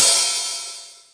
openhiht.mp3